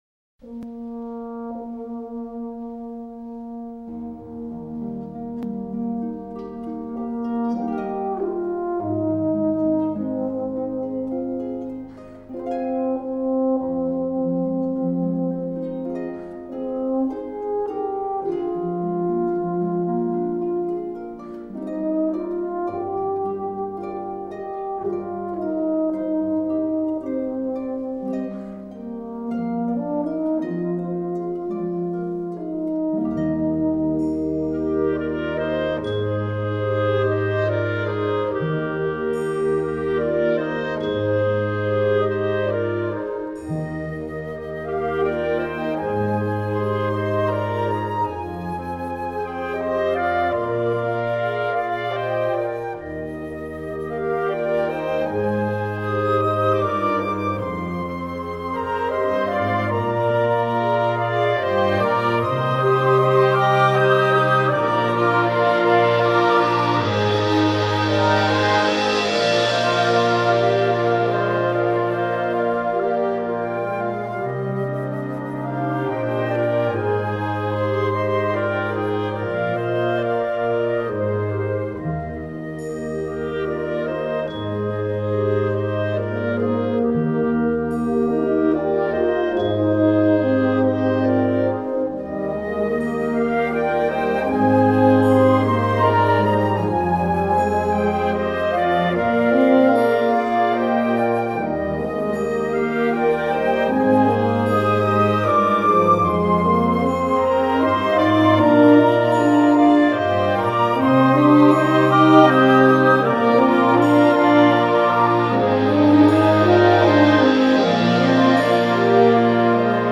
Voicing: Euphonium Solo w/ Band